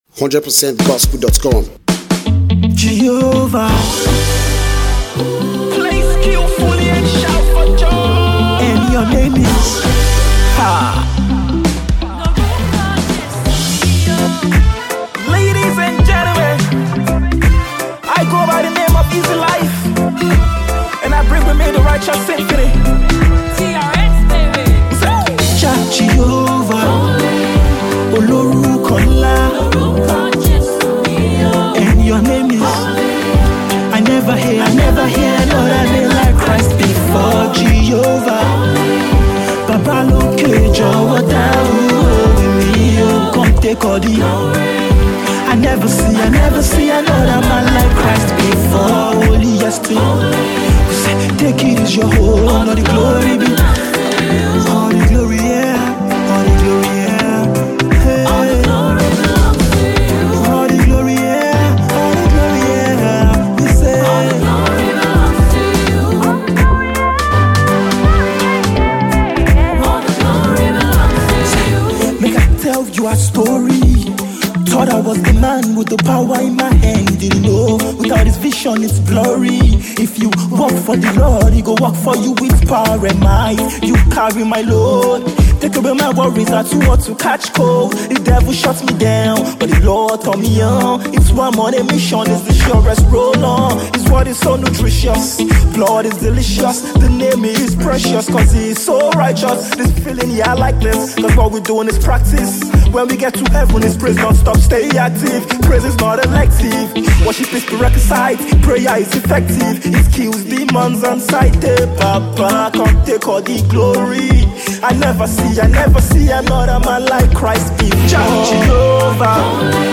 Gospel Reggae vibe